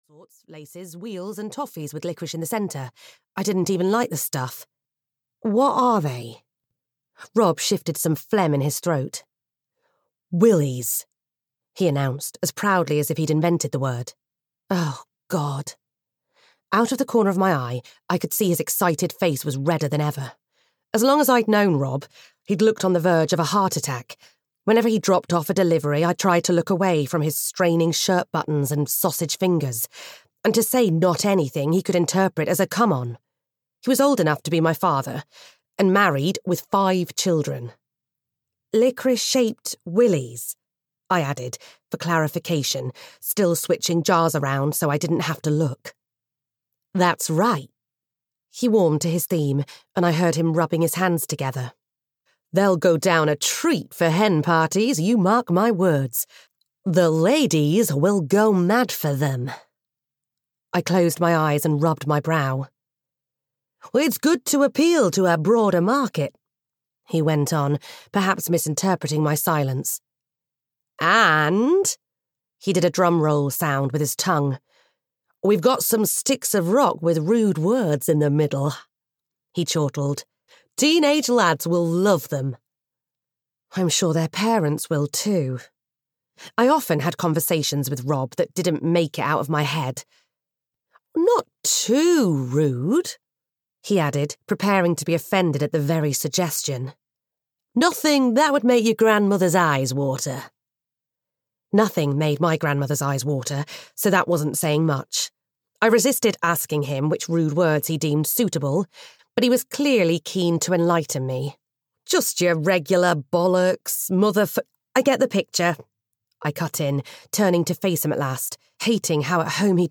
The Beachside Sweet Shop (EN) audiokniha
Ukázka z knihy